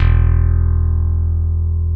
E1 2 F.BASS.wav